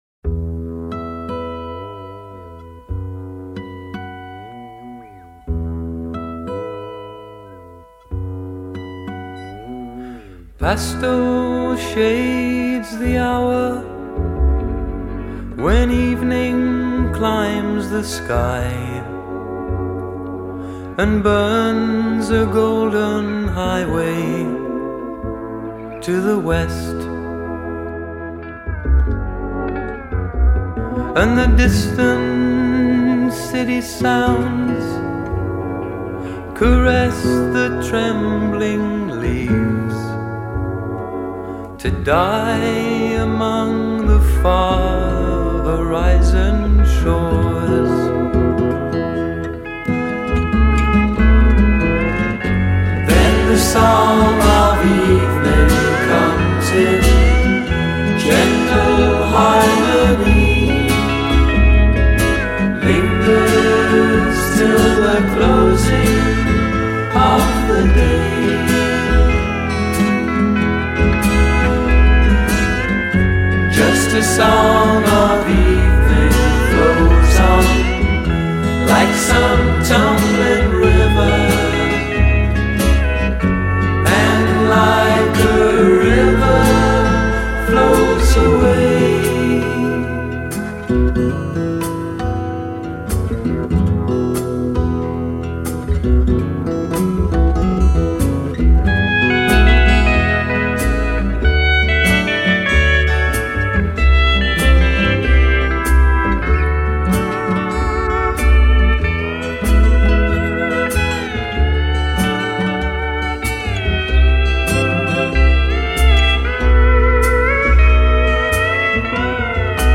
gently pastoral, anachronistic and very English folk rock